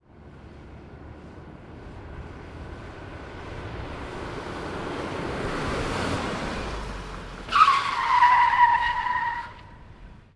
el squeeling of la llanta